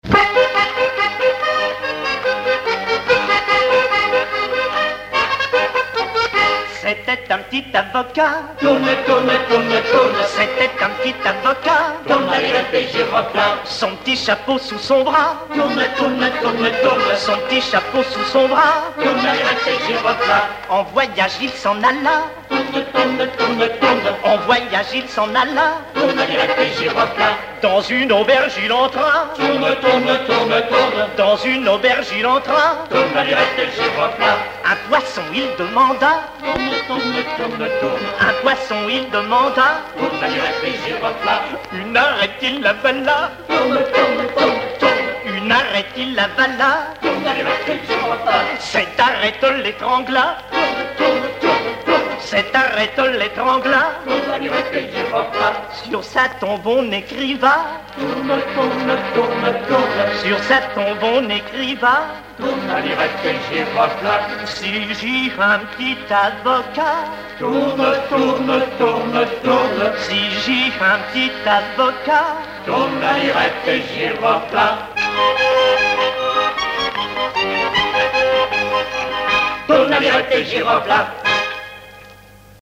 Rondes enfantines à baisers ou mariages
Pièce musicale inédite